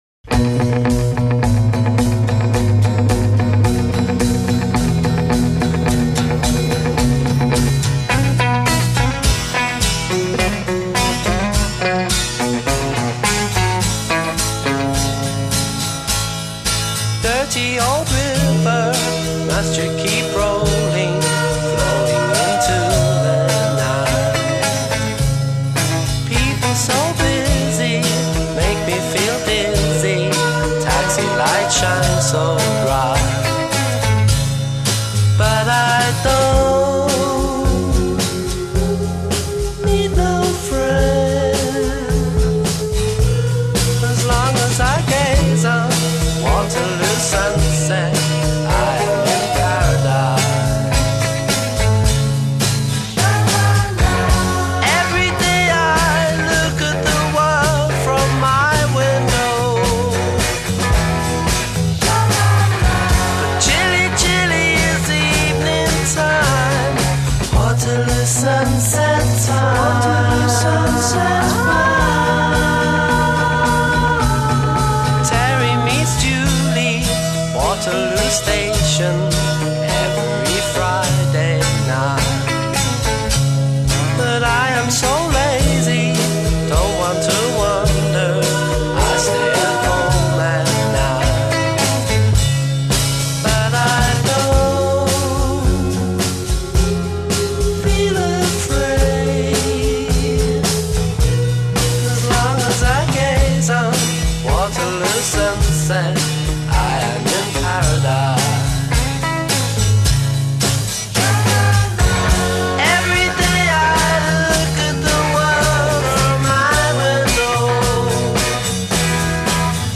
A verse a 8 + 8 Solo voice with backing vocals a
verse b 8 Rhythmic pace of the text slows b
coda 8+ Instrumental restatement of verse theme j